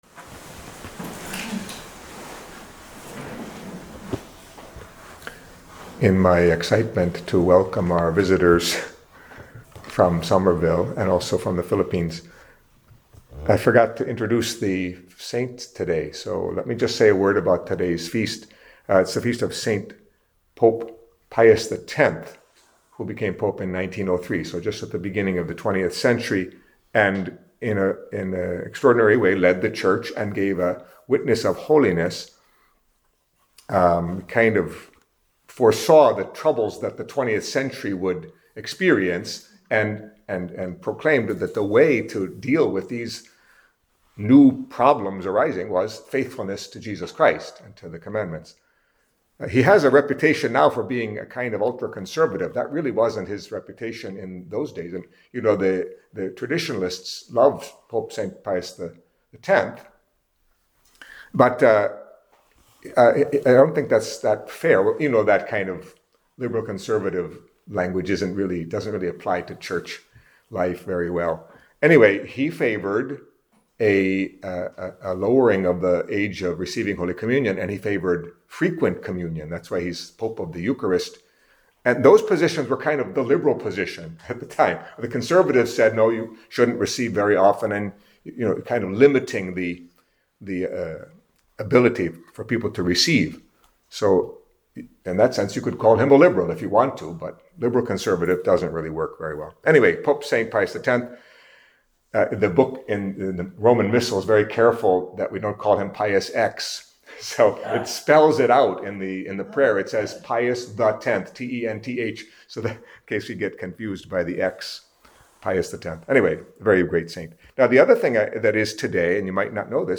Catholic Mass homily for Monday of the Twentieth Week in Ordinary Time